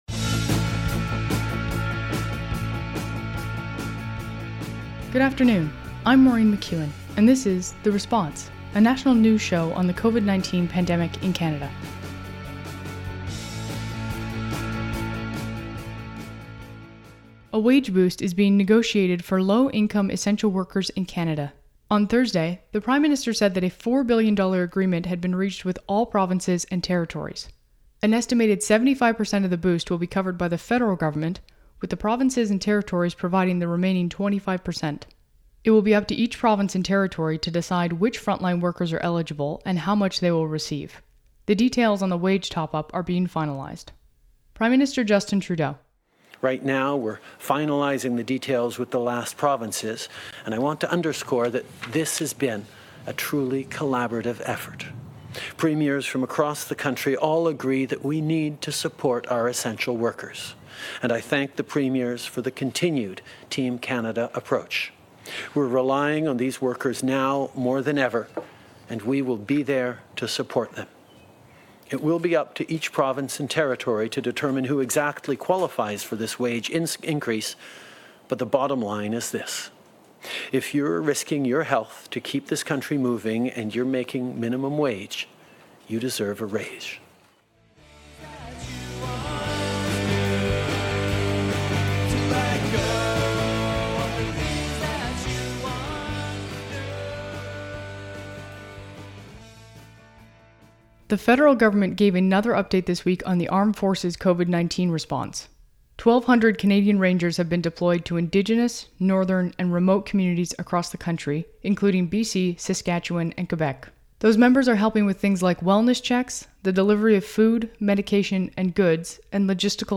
National News Show on COVID-19
Recording Location: Ottawa
Credits: Audio clips: Canadian Public Affairs Channel.
Type: News Reports